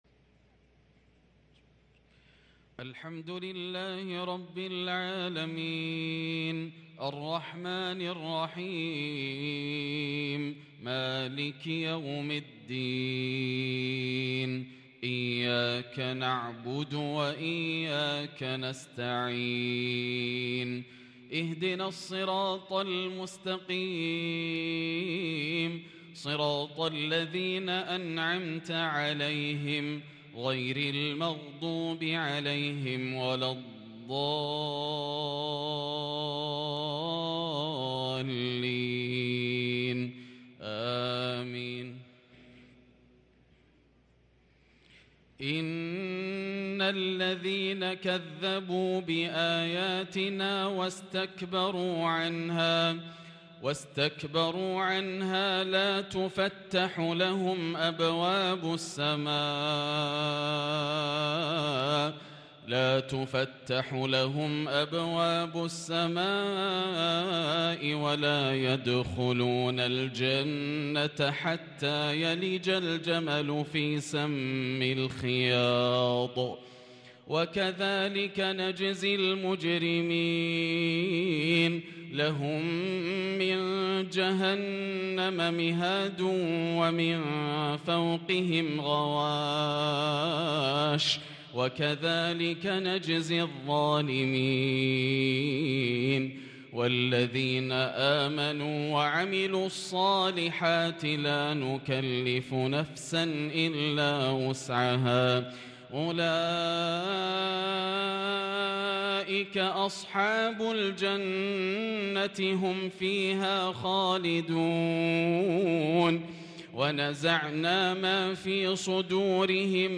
صلاة العشاء للقارئ ياسر الدوسري 9 ذو القعدة 1443 هـ
تِلَاوَات الْحَرَمَيْن .